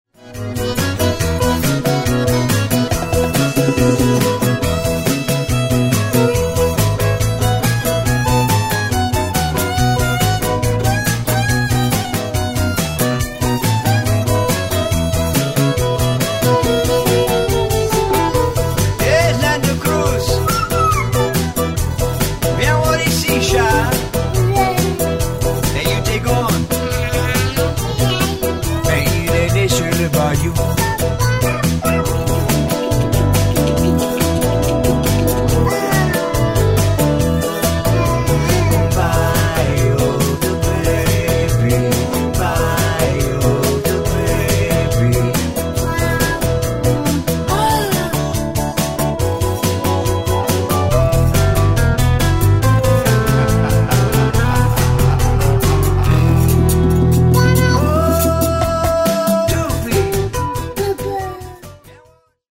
accordions and vocals and acoustic guitar
bass
petite fer